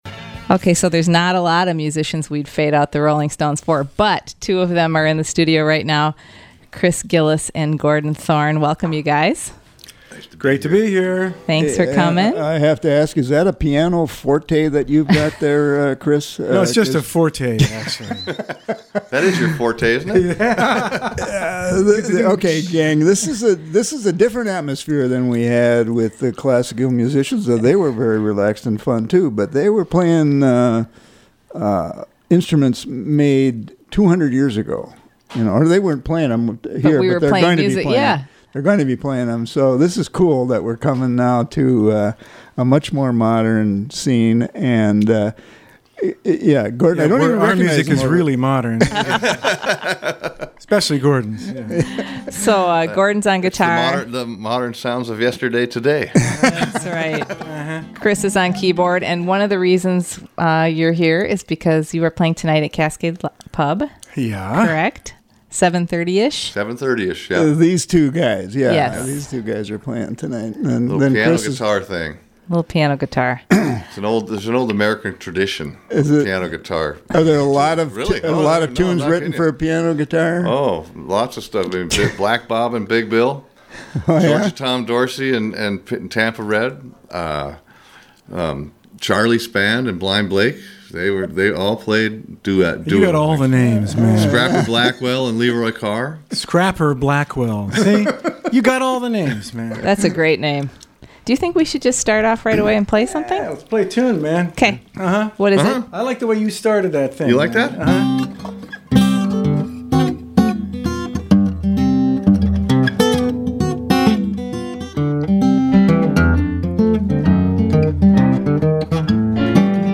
keyboard
guitar) to Studio A May 23 for a fun set of music and chat. Program: Live Music Archive The Roadhouse